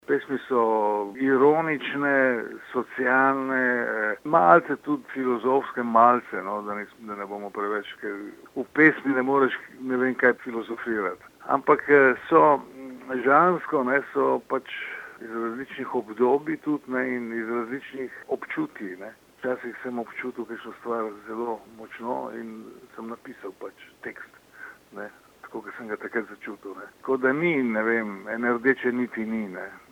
izjava_boriscavazza1.mp3 (348kB)